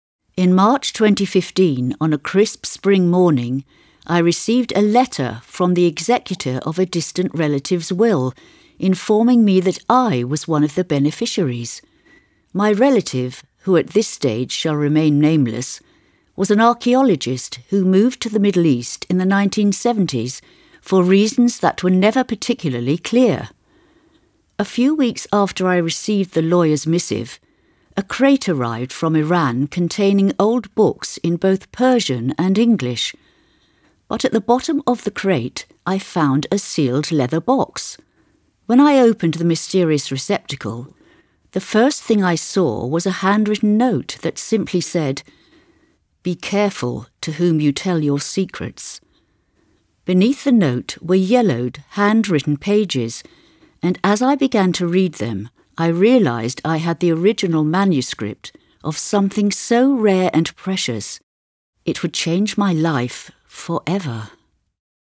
Female
English (British)
Adult (30-50), Older Sound (50+)
Audio Book Narration